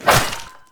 Flesh Hits